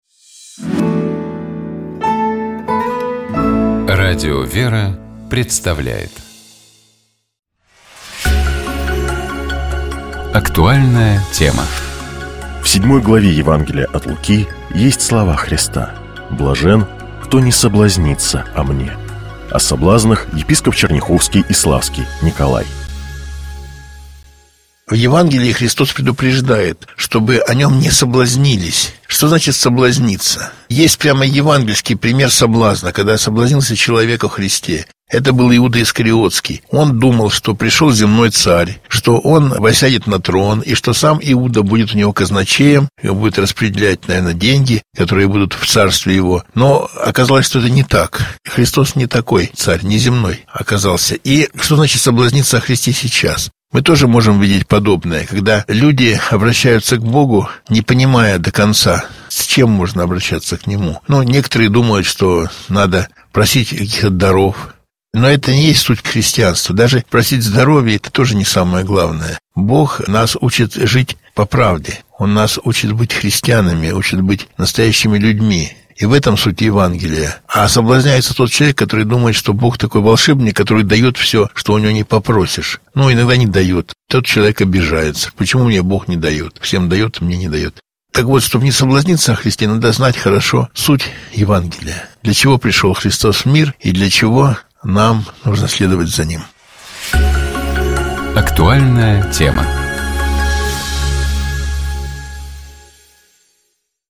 О соблазнах, — епископ Черняховский и Славский Николай.